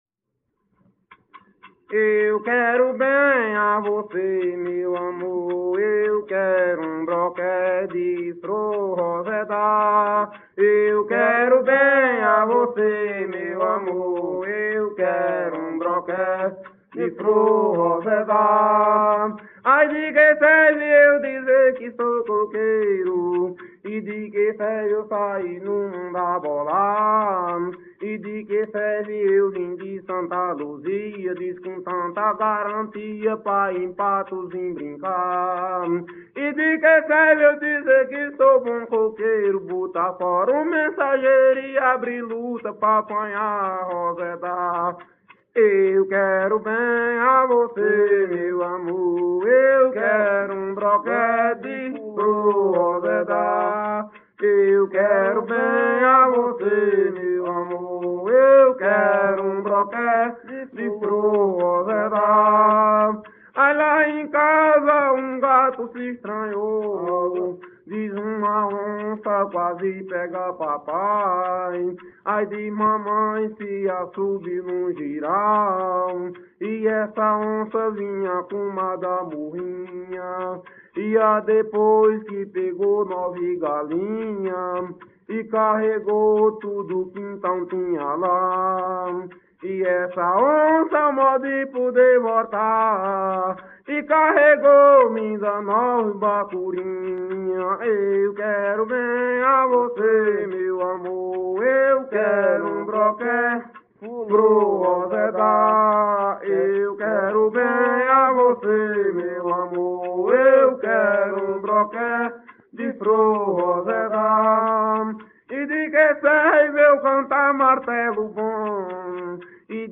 Coco parcelado -""Eu quero bem a você""